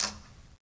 Quinto-Tap1_v1_rr2_Sum.wav